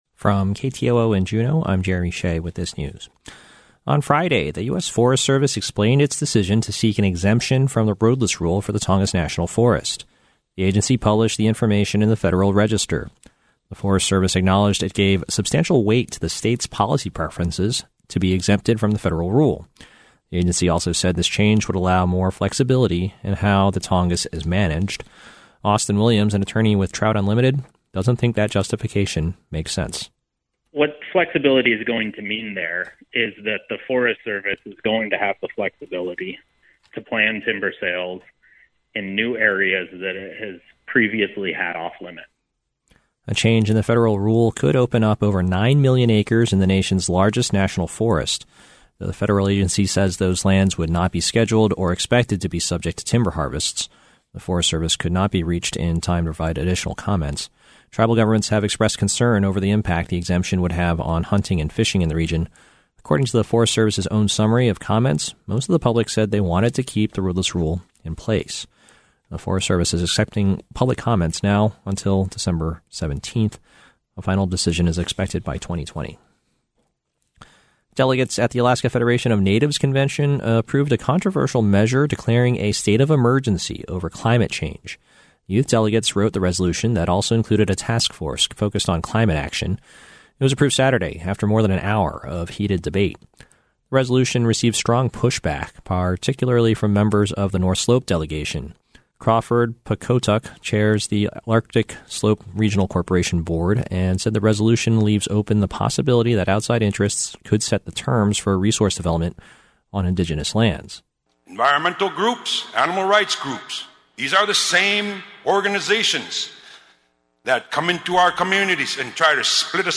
Newscast – Monday, Oct. 21, 2019